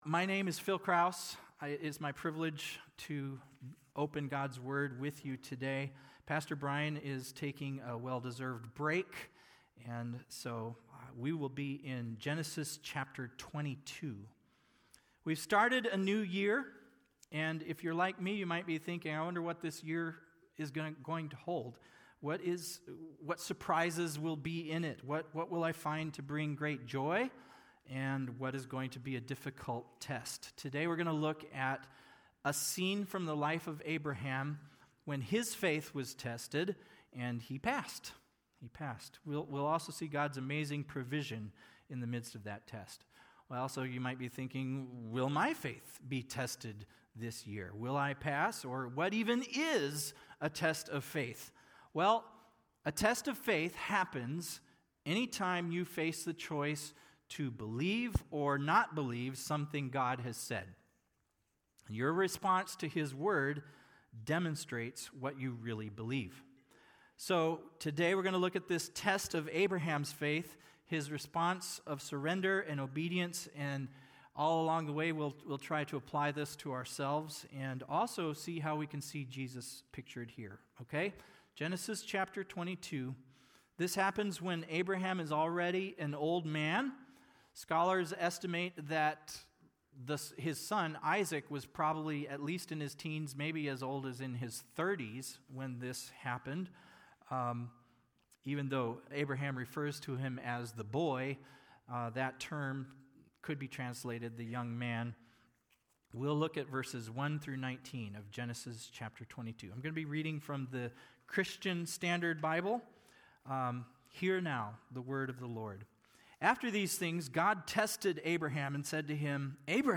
January 4, 2026 (Sunday Morning)